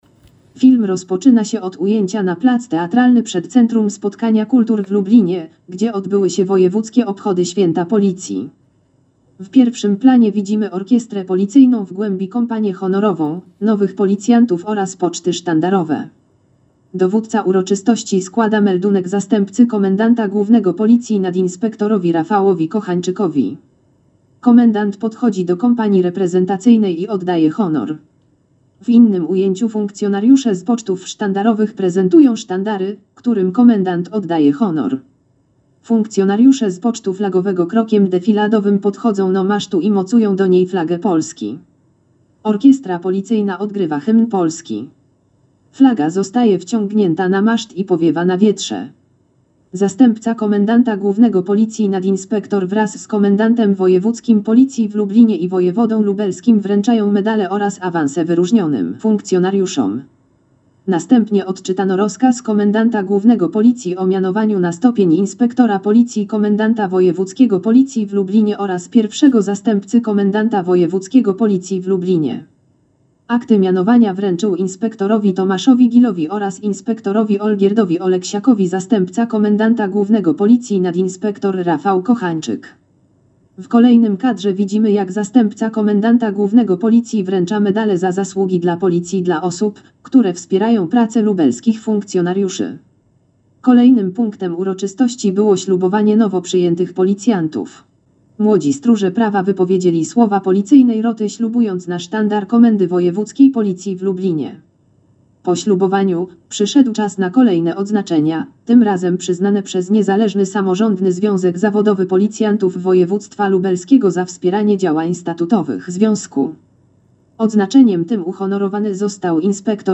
Nagranie audio Audiodeskrypcja filmu.